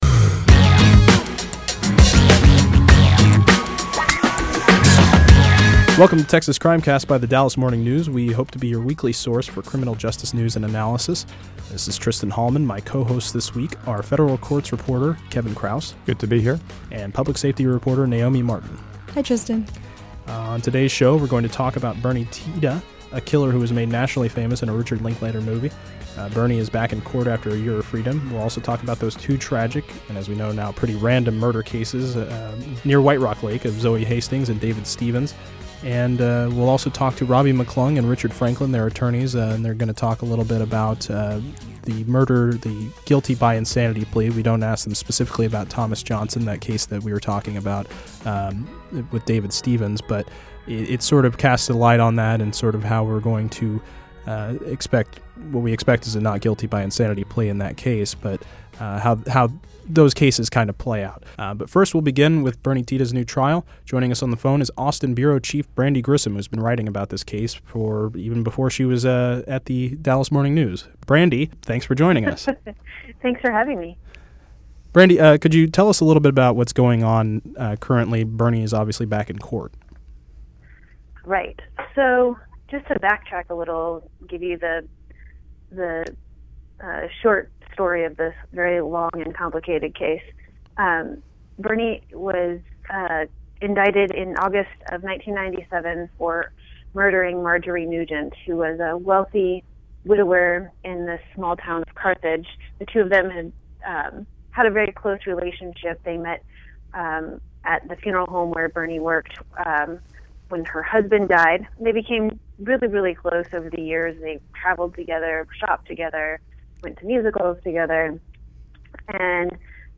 Defense attorneys discuss what actually goes into an insanity plea.